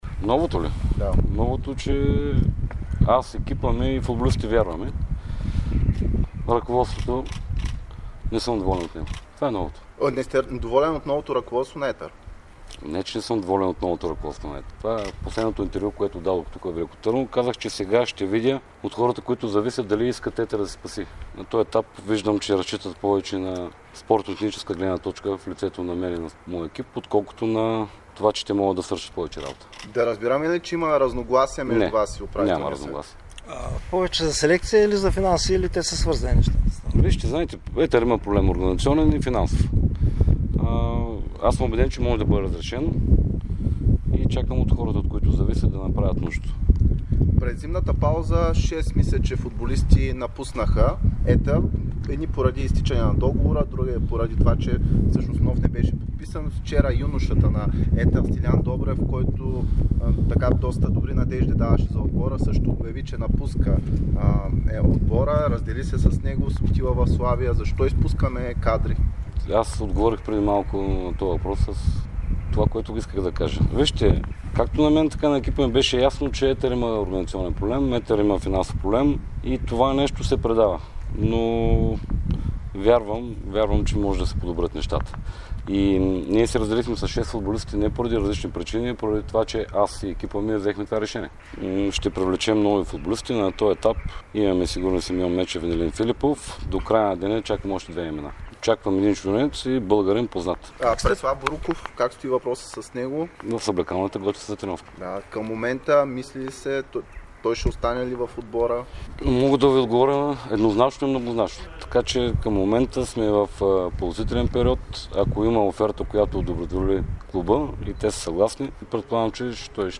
говори пред медиите на старта на зимната подготовка на "виолетовите".